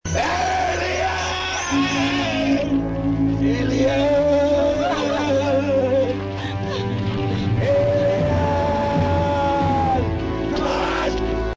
Joe can't reach the one on his back, so he improvises a new plan: He'll run at alien screaming, "ALIEN!" and Sandy will push the plunger when the alien gets close enough.